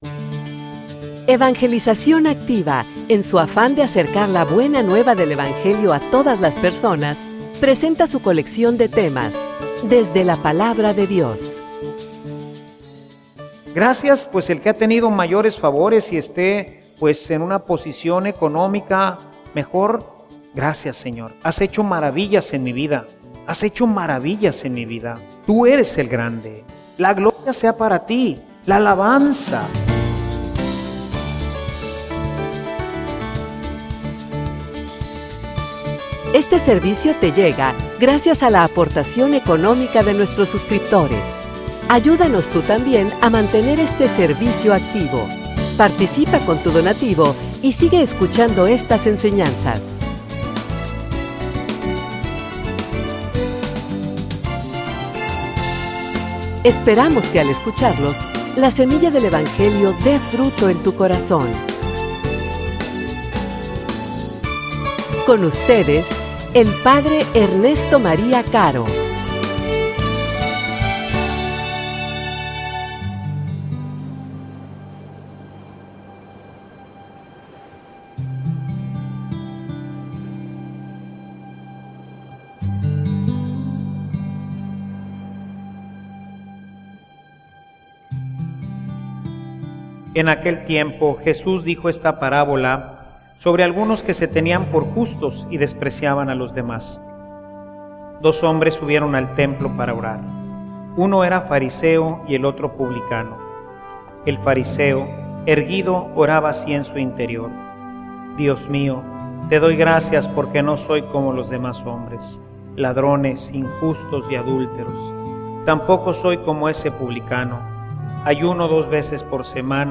homilia_Ubicate.mp3